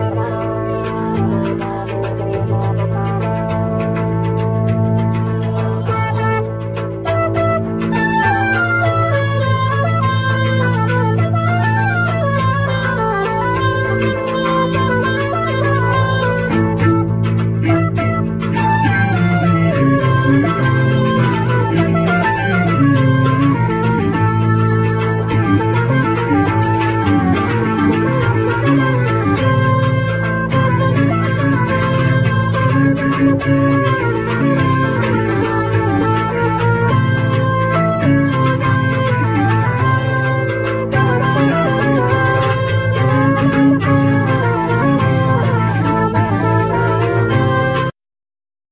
Basson, Oboe
Drums, Percussions, Radio
Bass, Guitar, Prepared guitar
Organ, Piano, Bass clarinet, Altsax, Xylophone, Percussions
Cello, electric cello, Voice
Piano, Synthsizer